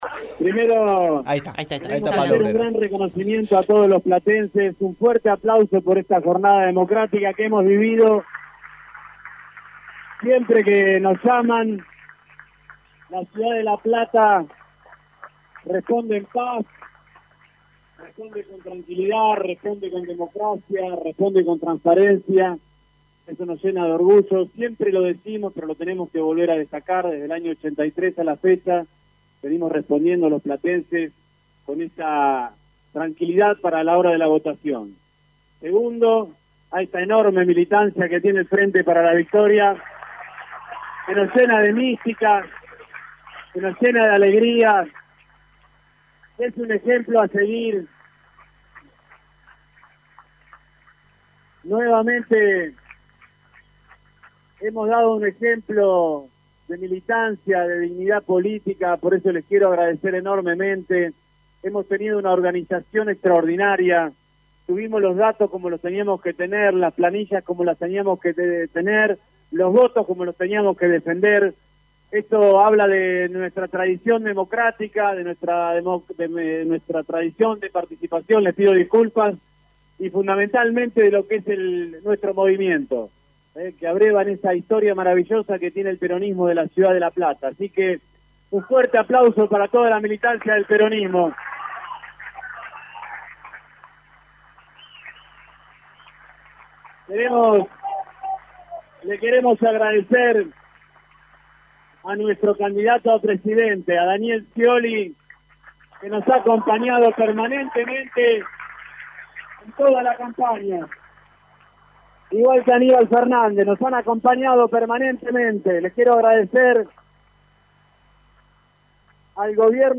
Desde su bunker, poco antes de la medianoche el actual intendente de la ciudad Pablo Bruera salió a reconocer su derrota y dijo: «Tengo que hacer un gran reconocimiento a todos los platenses y dar un fuerte aplauso por esta jornada que hemos vivido. Siempre que nos llaman, la ciudad de La Plata responde en paz y de forma democrática».
Pablo Bruera en el bunker